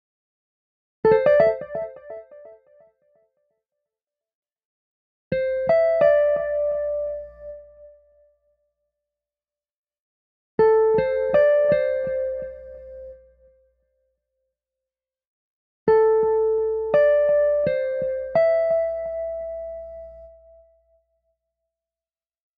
吉他环1只钩
标签： 85 bpm Hip Hop Loops Guitar Acoustic Loops 3.80 MB wav Key : Unknown
声道立体声